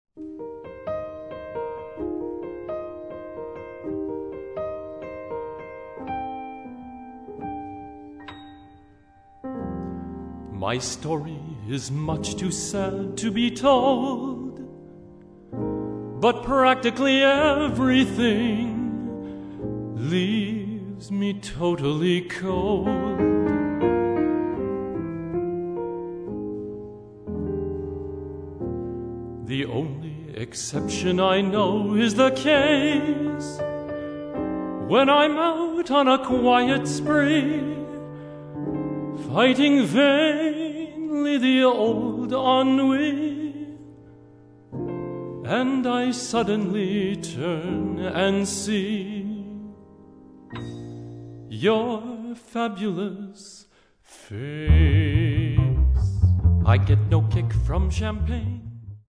Vocals
Piano
Bass
Drums